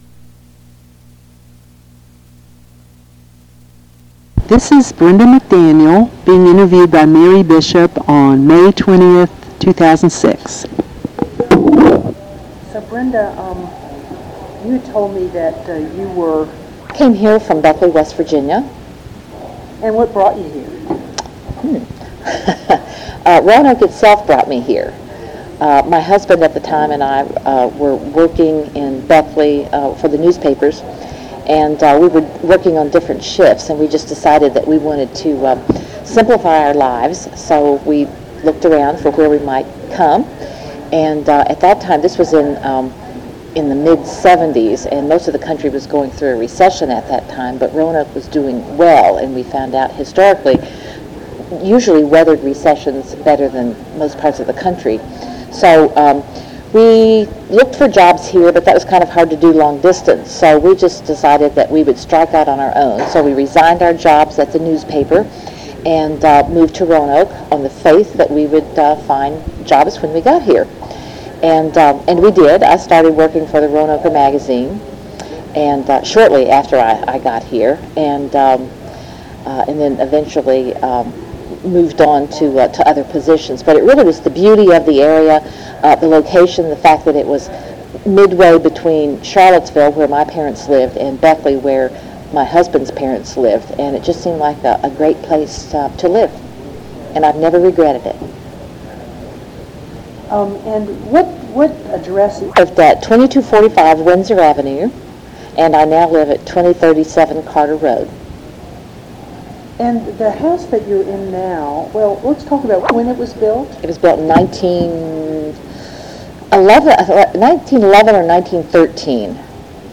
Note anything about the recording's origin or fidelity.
Location: Greater Raleigh Court Civic League Block Party